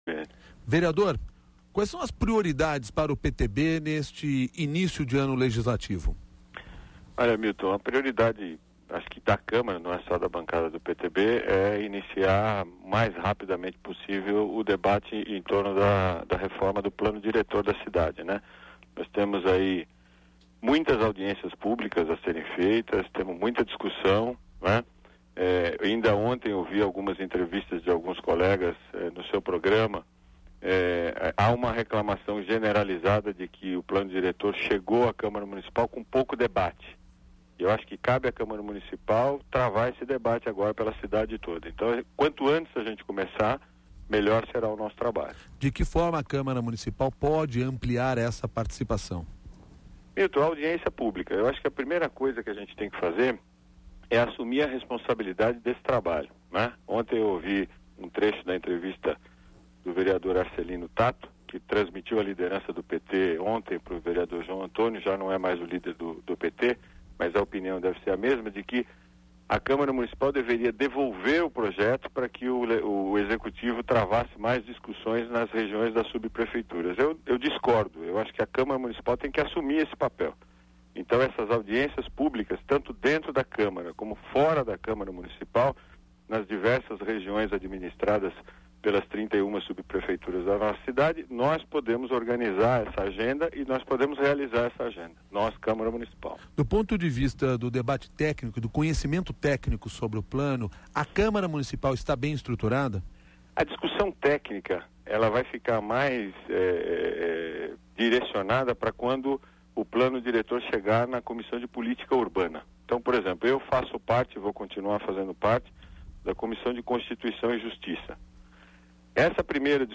Para reduzir este período, o vereador “adotado” Celso Jatene (PTB), propõe mudança na lei orgânica do município extinguindo o recesso no meio do ano. Ele anunciou a medida durante entrevista ao CBN São Paulo, nesta terça-feira.